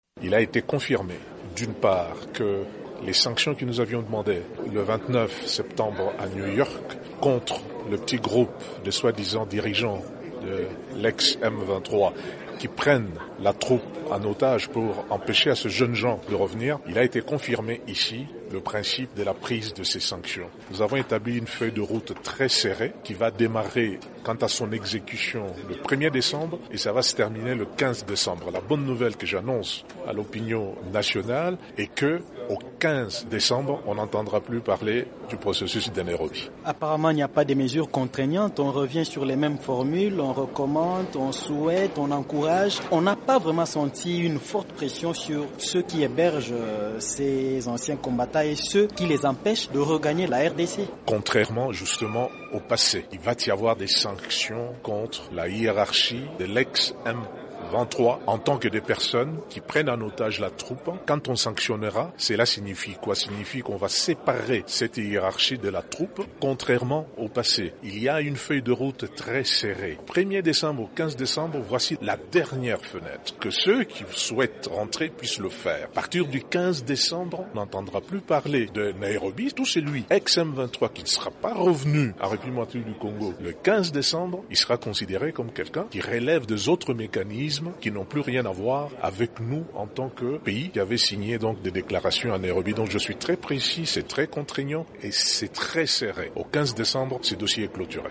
François Mwamba au micro de TOP CONGO FM, notre station partenaire à Kinshasa